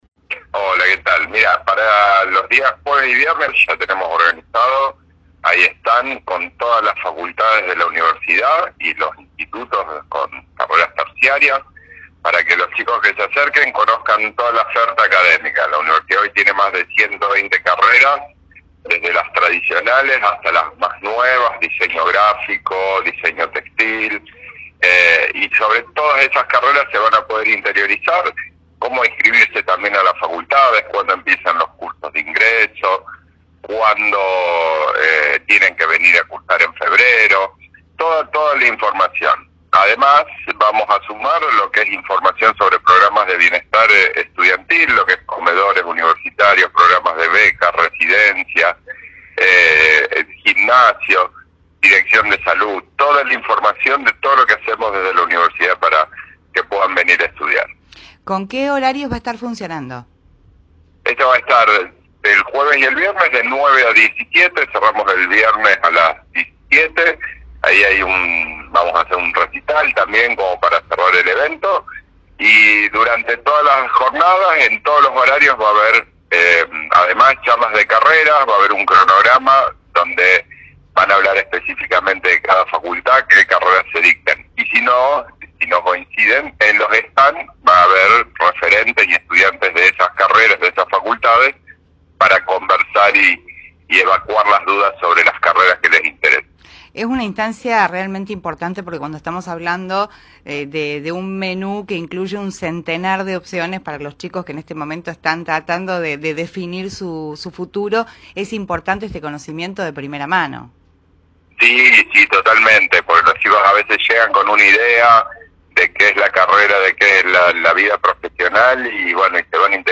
dialogó con Primera Plana de Cadena 3 Rosario y brindó detalles de la Expo Carreras de la Universidad Nacional de Rosario (UNR).